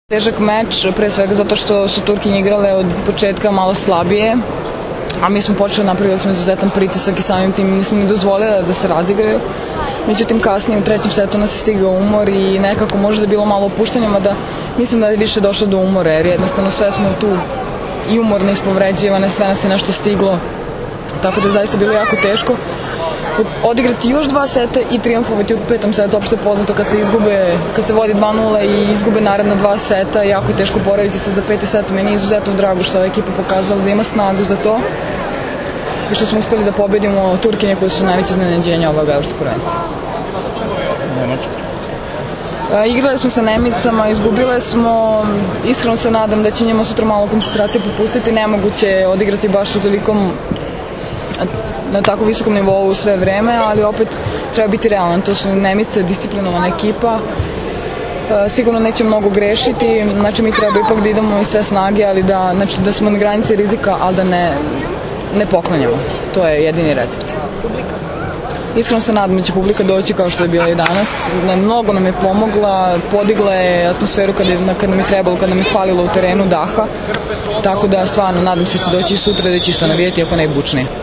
IZJAVA JOVANE BRAKOČEVIĆ